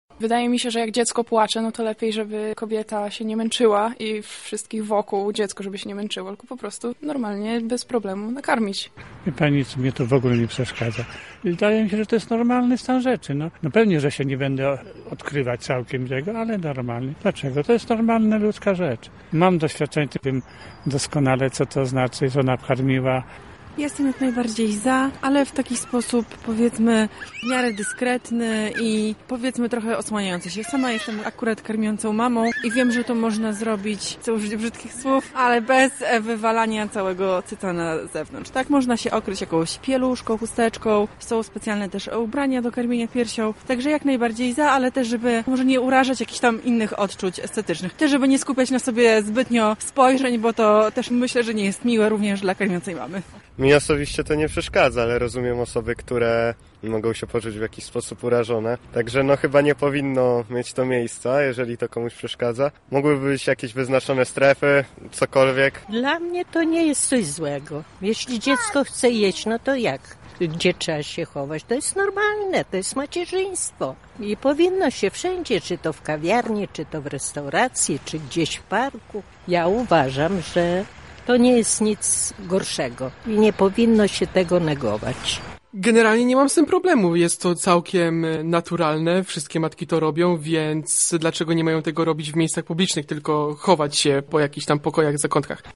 Nasza reporterka wyszła na ulice Lublina i zapytała, czy dla mieszkańców naszego miasta – widok kobiety karmiącej dziecko w sposób naturalny to problem:
Sonda o karmieniu piersią
sonda-karmienie-piersia.mp3